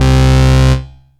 SYNTH BASS-1 0016.wav